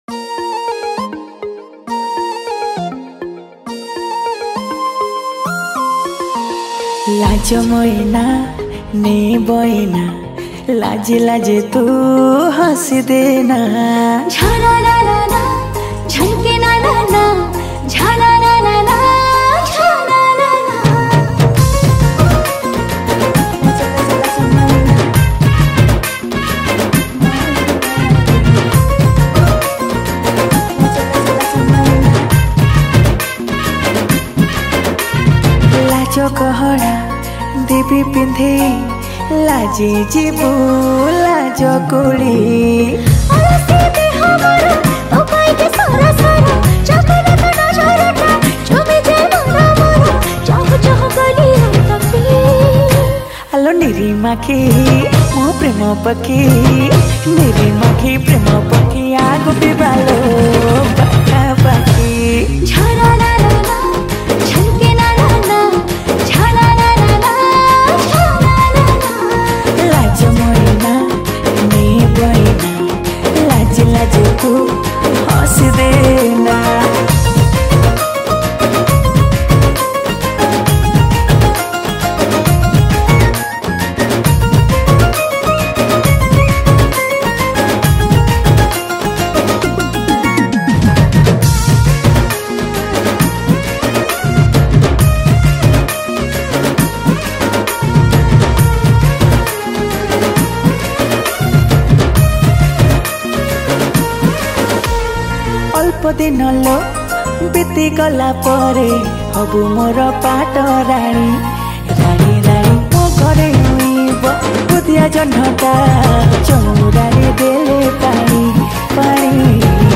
Dhol & Nisan